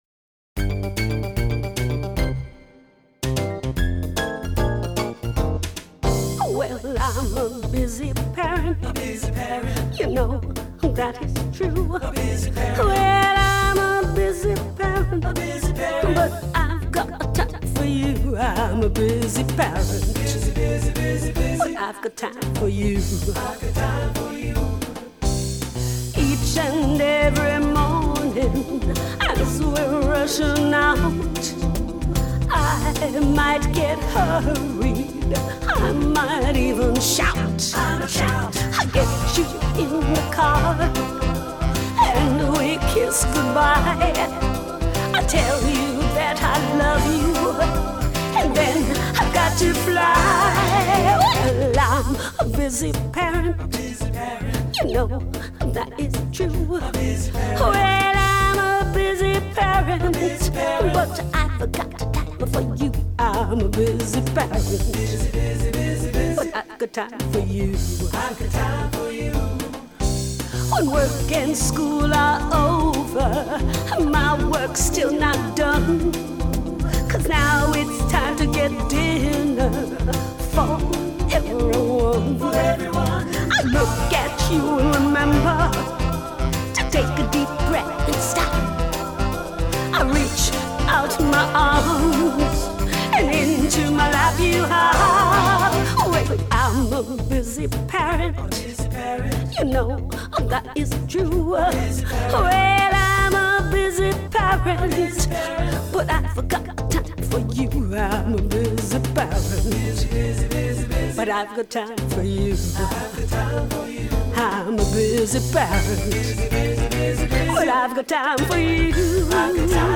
Genre: Childrens.